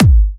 VEC3 Bassdrums Trance 75.wav